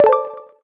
skill_select_01.ogg